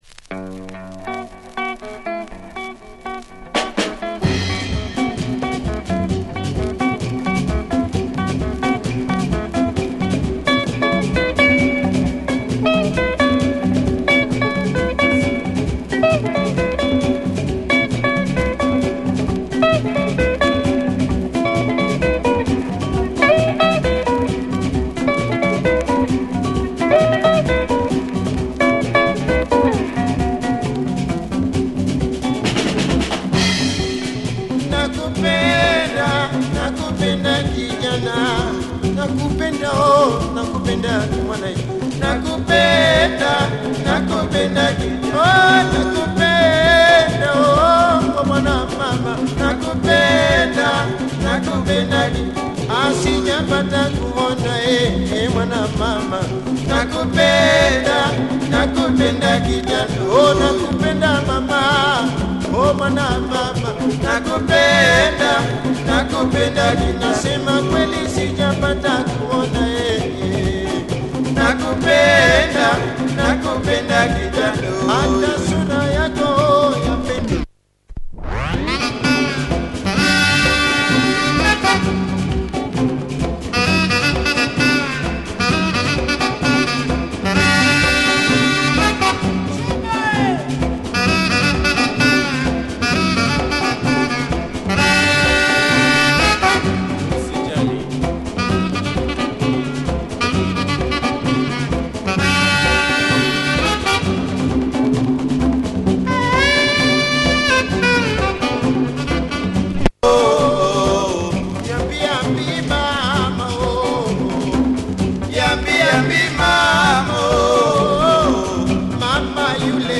Kick-ass sax breakdown and rumbling backbone.
Has a few superficial marks, but barely audible.